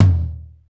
TOM R B L0QL.wav